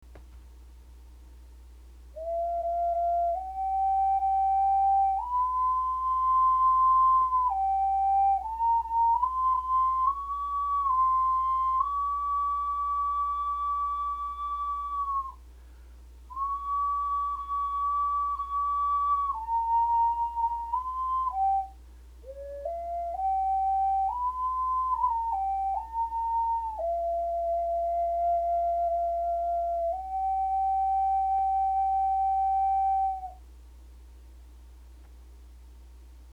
2）楽譜にはテンポの表記がないが、私が頂戴した複製テープで聴くと、おおよそ四分音符＝66ほどのテンポ。
「オカリナの音色を『見る』」で書いている「空気を入れる」「風を吹き込む」それぞれの吹き方で吹いてみましたが、技量未熟のためあまり特色が出せていません。